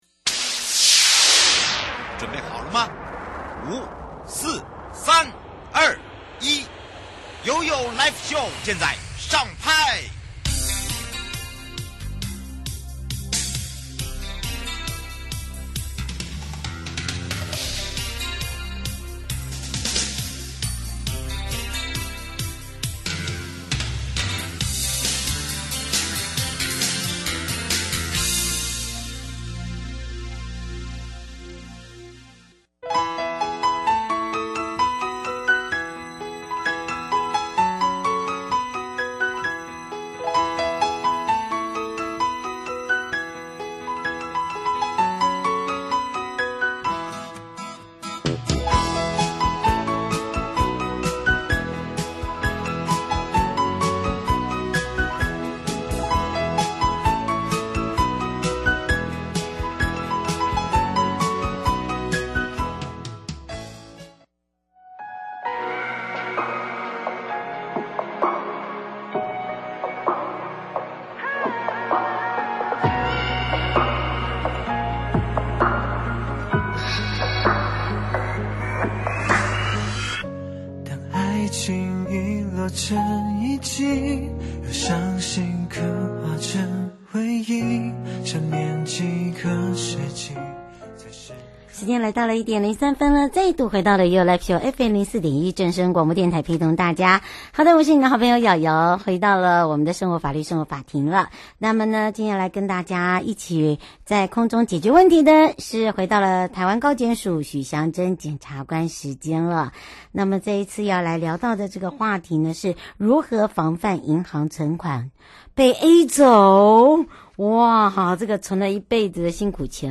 受訪者： 台灣高檢署許祥珍檢察官 節目內容： 題目：如何防範銀行存款 被A走！